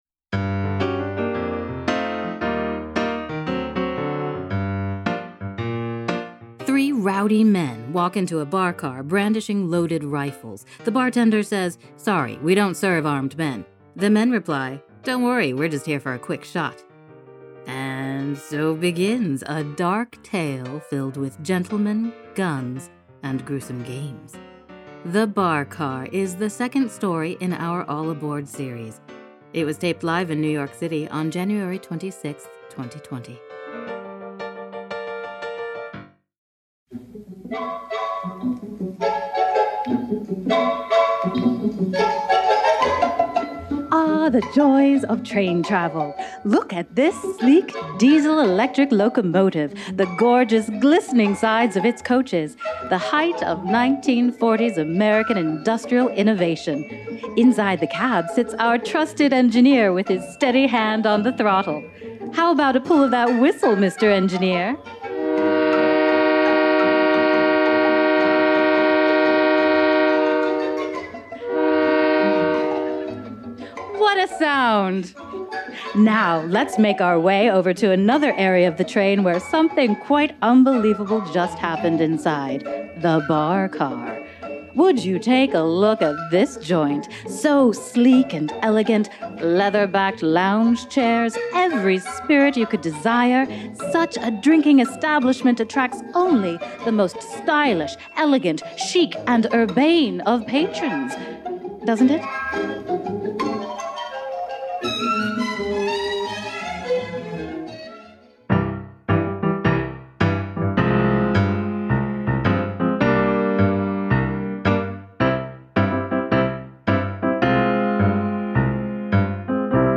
However, a few rampageous passengers have other ideas. All Aboard! was recorded live in NYC on January 26, 2020.